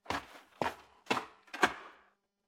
Saddle Movement
A leather saddle shifting and creaking with rider weight movement and strap tension
saddle-movement.mp3